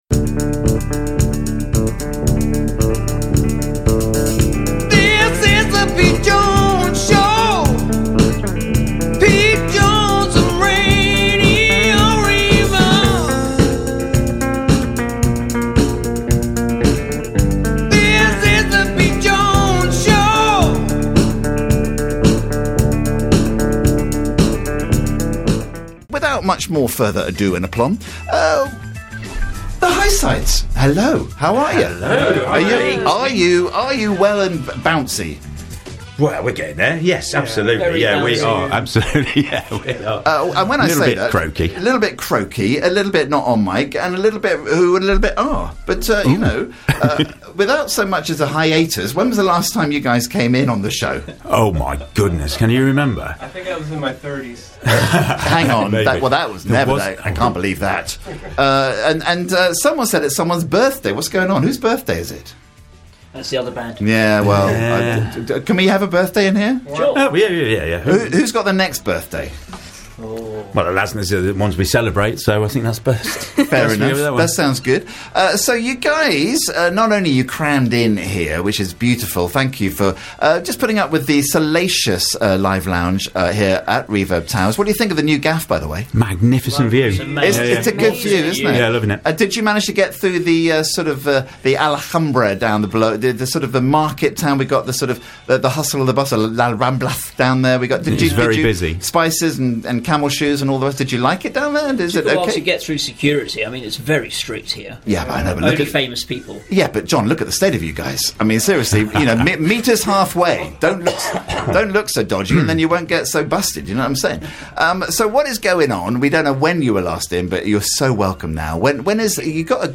in the studio kicking off live for us